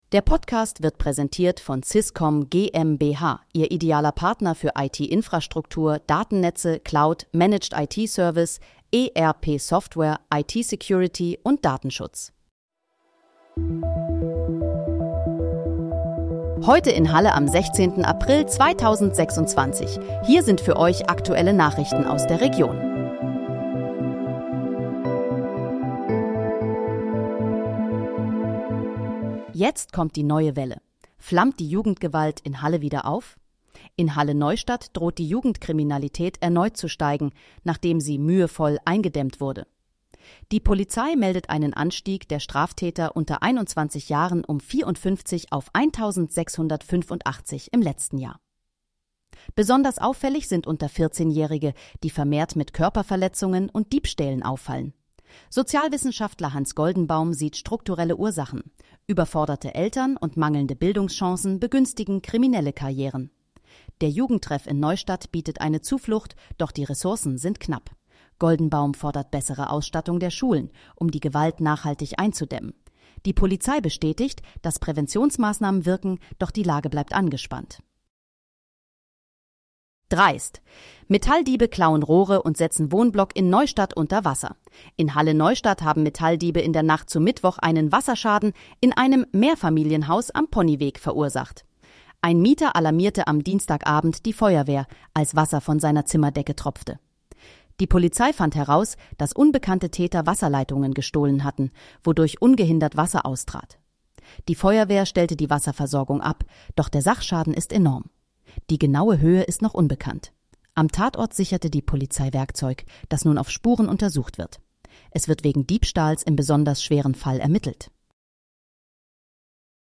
Heute in, Halle: Aktuelle Nachrichten vom 16.04.2026, erstellt mit KI-Unterstützung